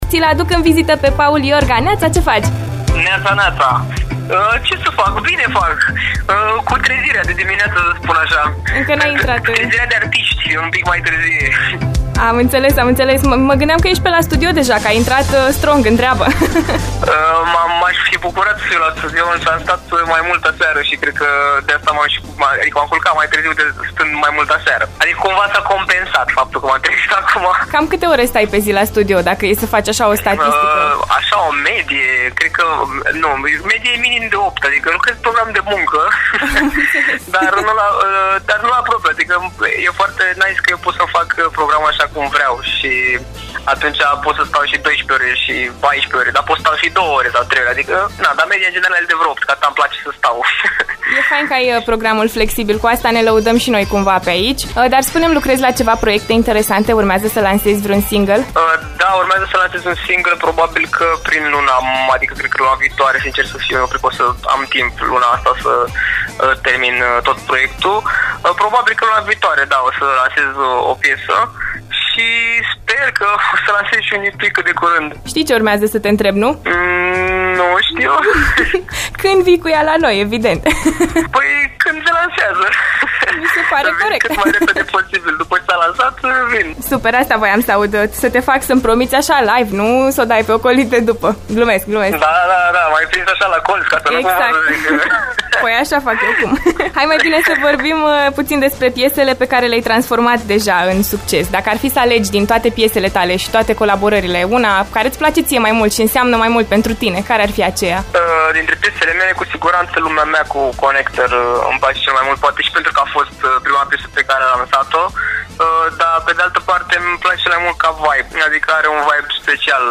Interviu cosmic